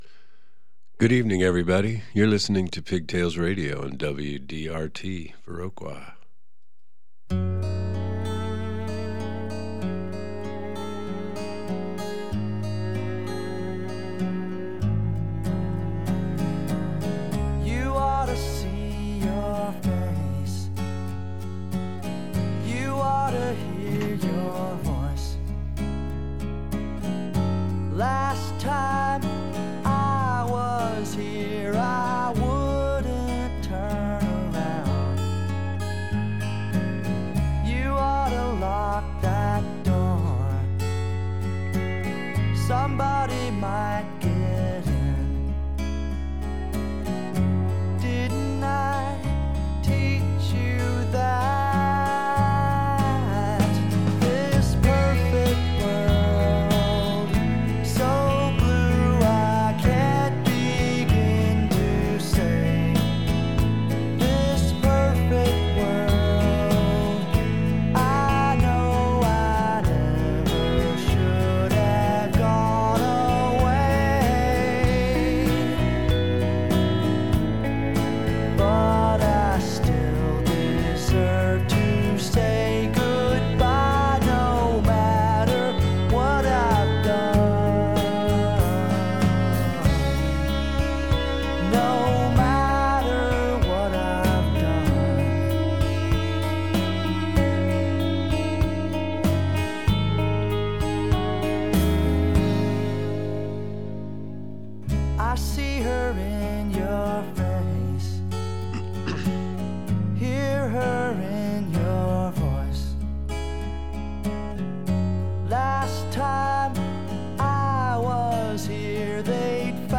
Interview with Freedy Johnston - WDRT